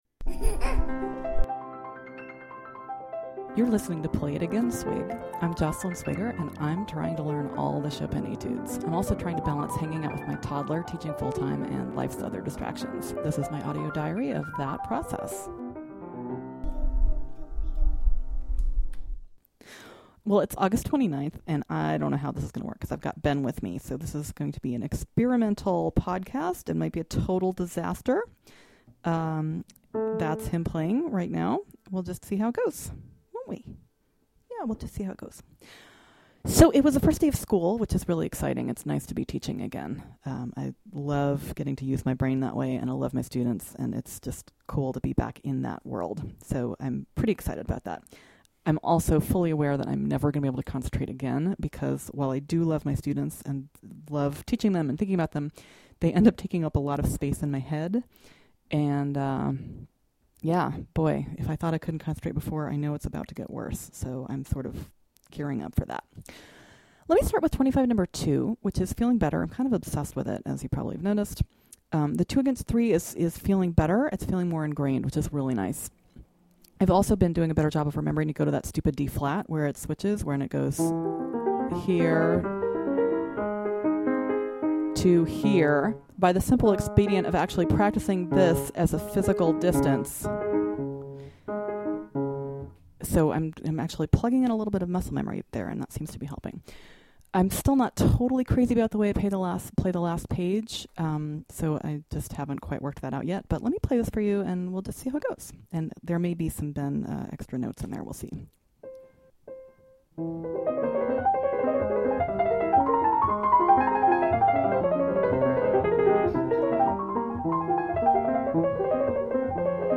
A performance of 25/2; a crash-through of 25/11; a minimalist improvisation based on one measure of 25/11; a special guest star gives an accurate picture of some of my practice.